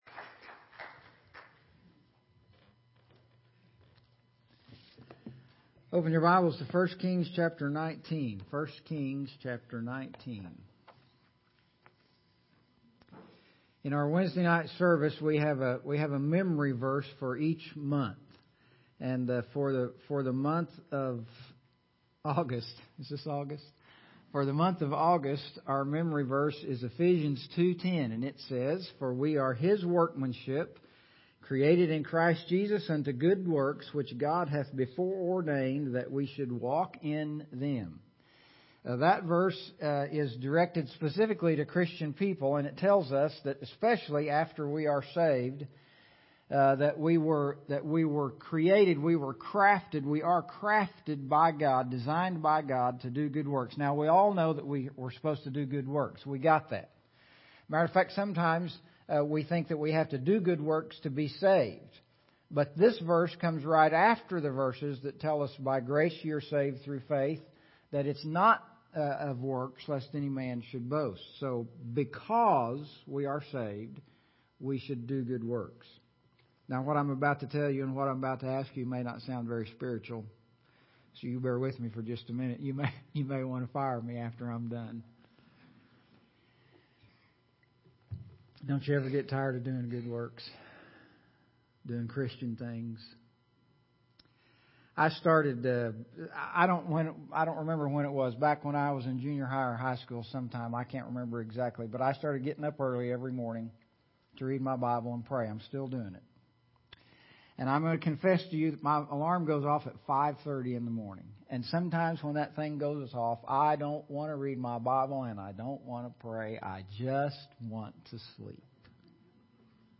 First Free Will Baptist Church - Archived Sermons